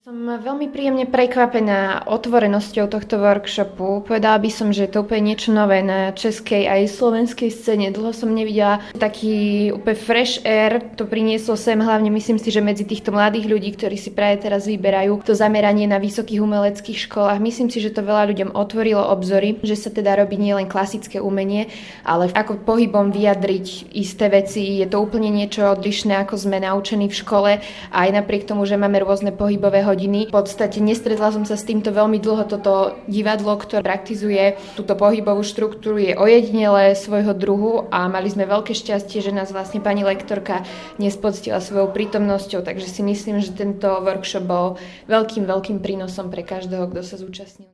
Svoje pocity vyjadrila účastníčka dielne